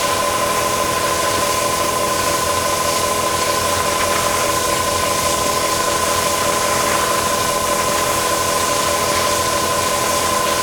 Sfx_tool_spypenguin_move_01.ogg